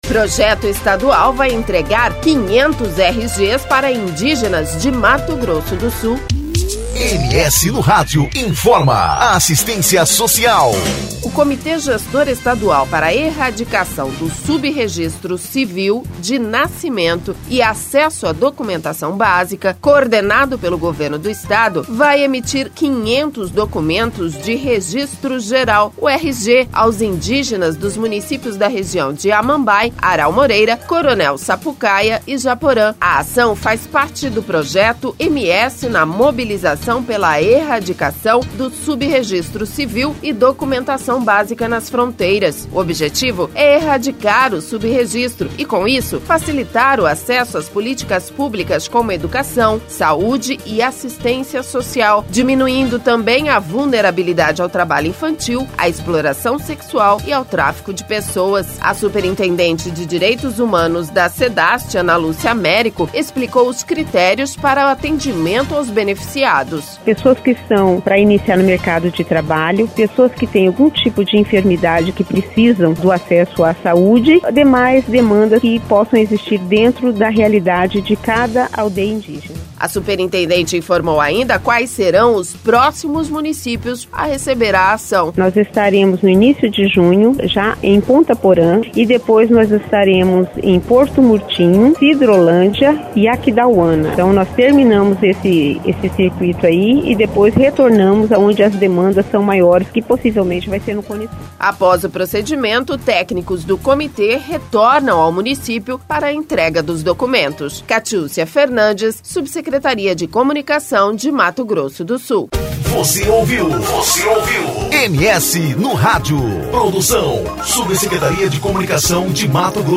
A superintendente de Direitos Humanos da Sedhast, Ana Lúcia Américo explicou os critérios para o atendimento aos beneficiados.